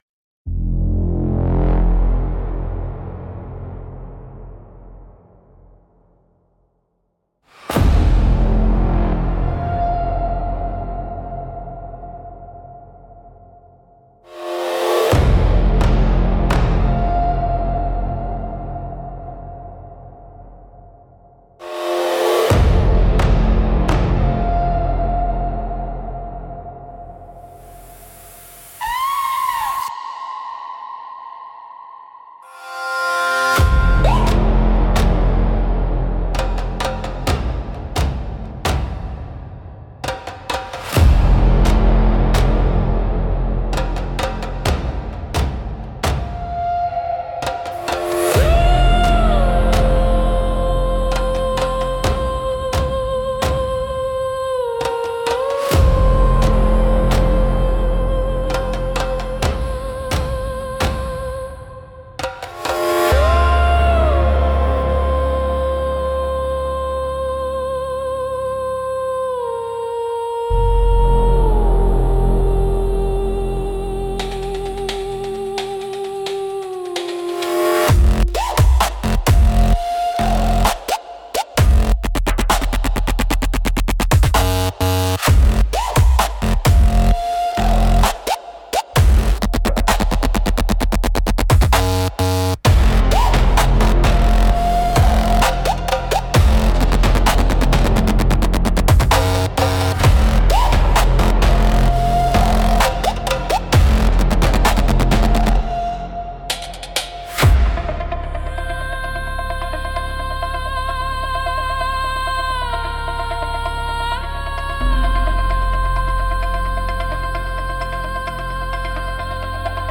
Instrumental - Firewalker’s Chant - 2.58